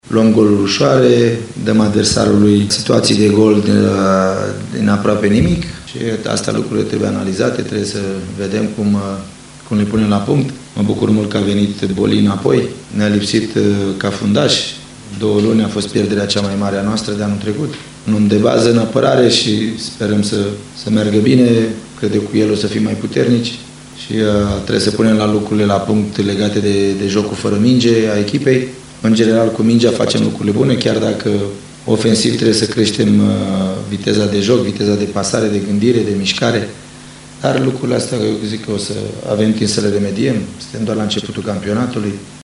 Antrenorul Viitorului Constanța, Gică Hagi, a tras la rândul său concluziile jocului de pe „Dan Păltinișanu”: